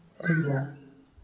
speech
keyword-spotting
speech-commands